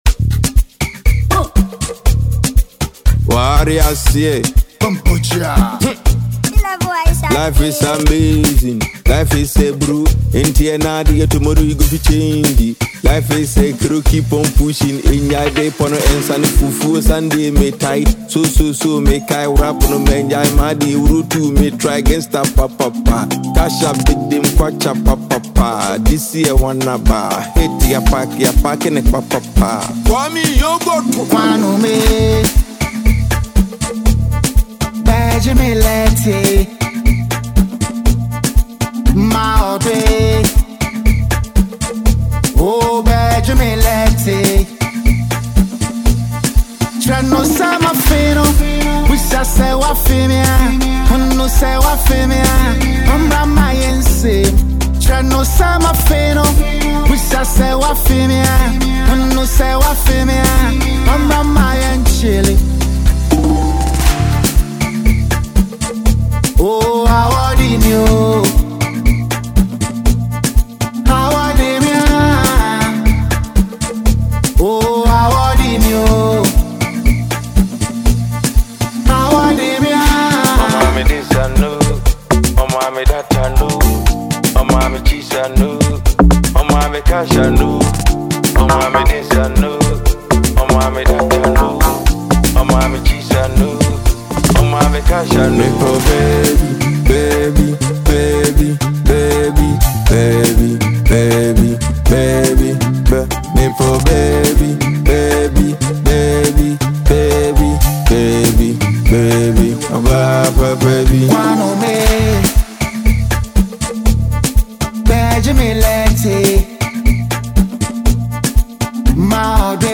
Ghanaian multi-talented musician and songwriter
afrobeat song